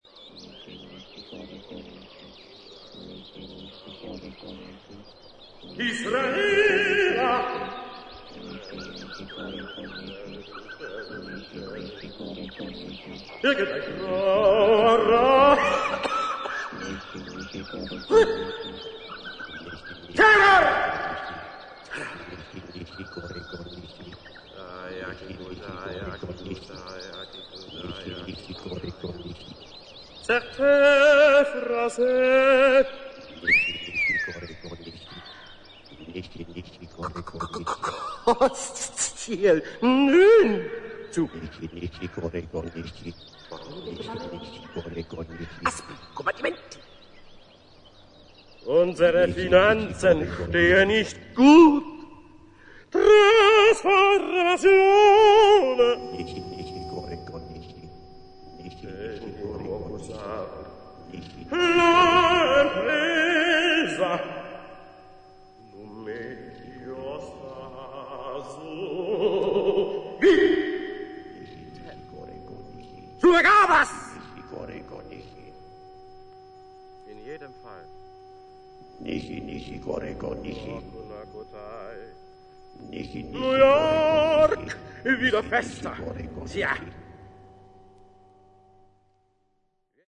1975年にドイツ・シュトゥットガルトで行ったライヴ・レコーディング盤